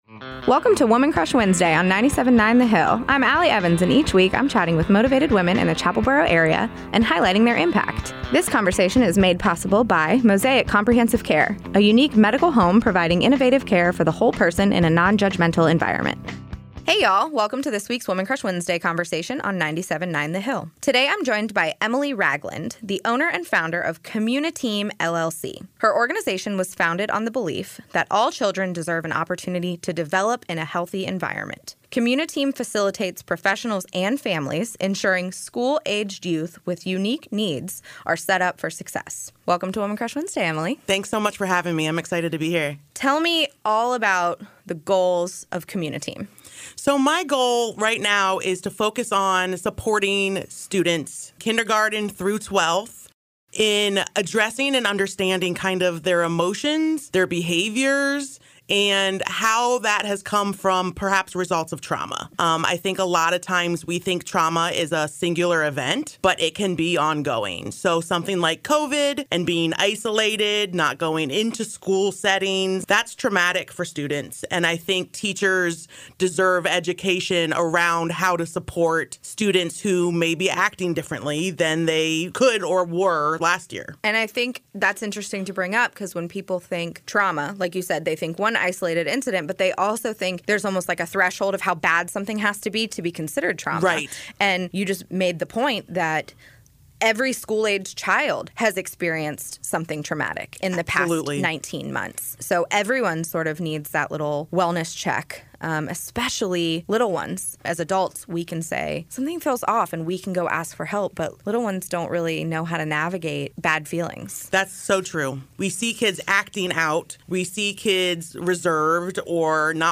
an extended version from what aired on 97.9 The Hill